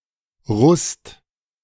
Rust (German: [ʁuːst]